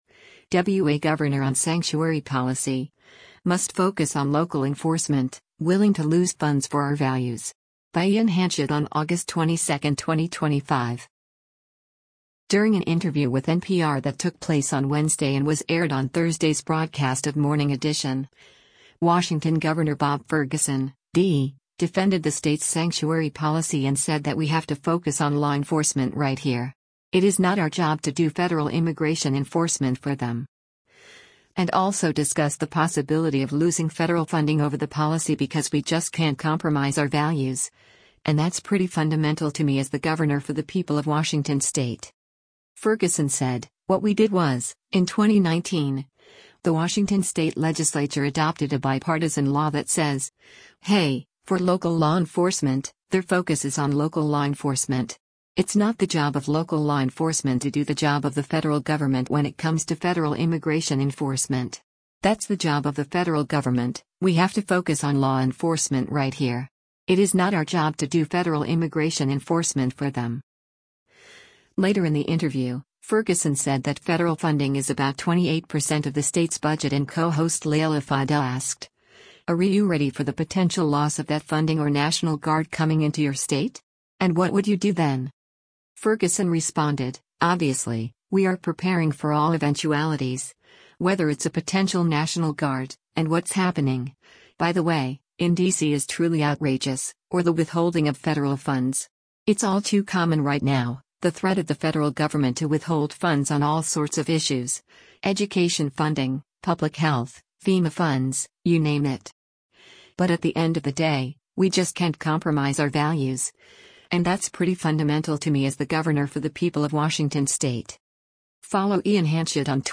During an interview with NPR that took place on Wednesday and was aired on Thursday’s broadcast of “Morning Edition,” Washington Gov. Bob Ferguson (D) defended the state’s sanctuary policy and said that “We have to focus on law enforcement right here. It is not our job to do federal immigration enforcement for them.” And also discussed the possibility of losing federal funding over the policy because “we just can’t compromise our values, and that’s pretty fundamental to me as the governor for the people of Washington state.”